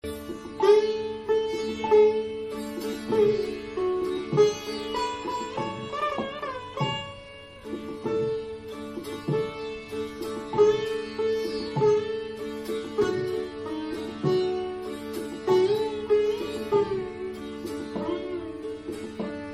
シタール-
演奏する時は主に上層の弦を弾き、下層の弦は共鳴弦となります。
Sitar.mp3